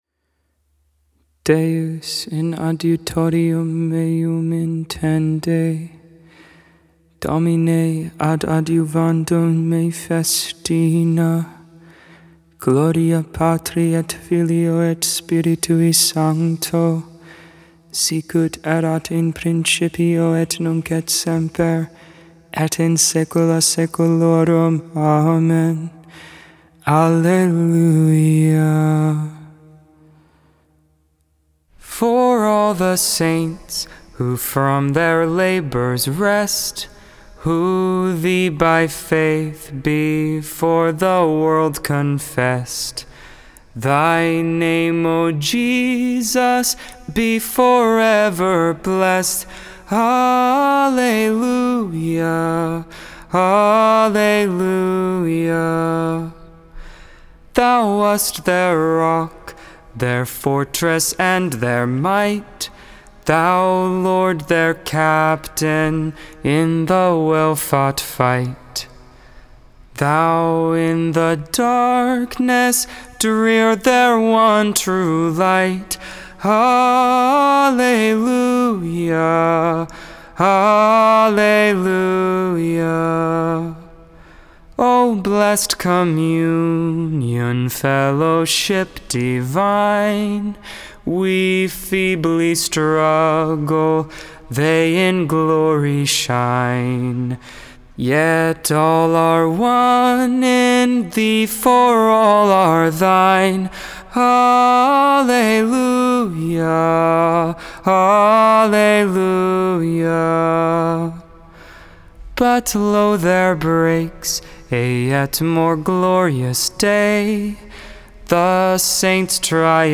5.30.22 Vespers, Monday Evening Prayer